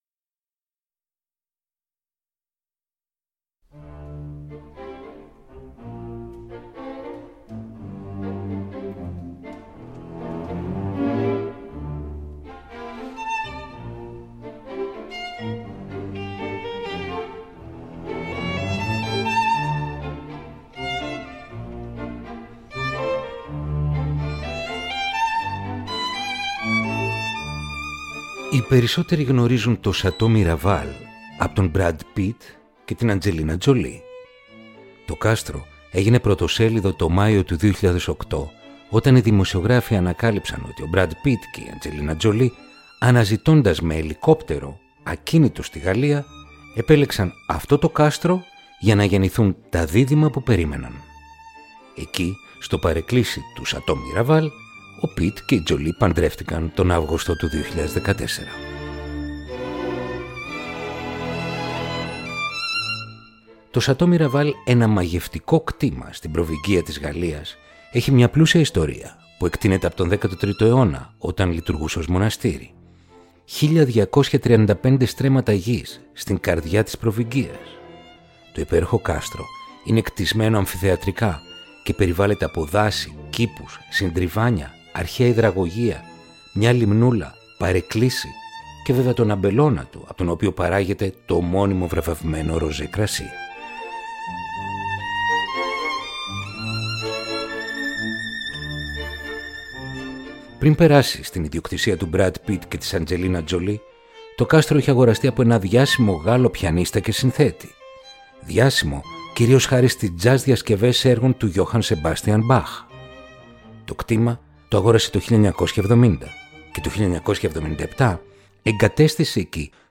Έργα για Κρουστά – 7.
Jacques Loussier Concerto for Violin and Percussion Libby Larsen Marimba Concerto